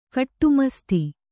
♪ kaṭṭumasti